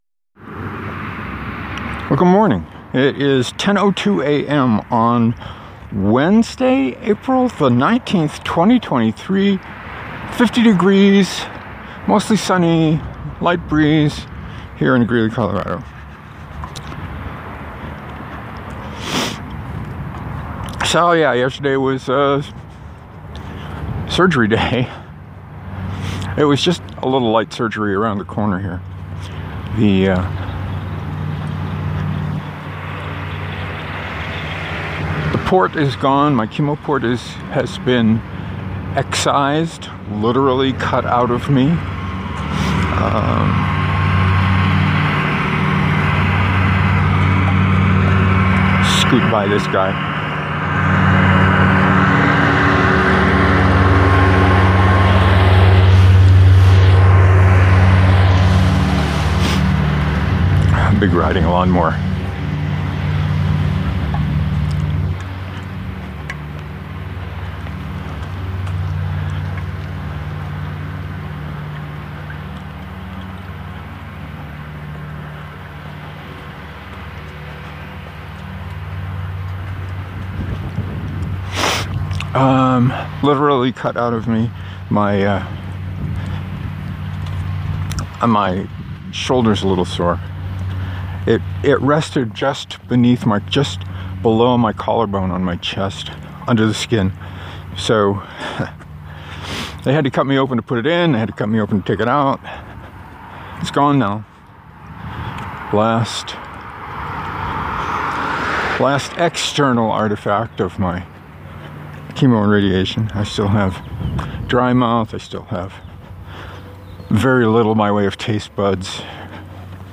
The transition probably sounds funny but that’s the cause.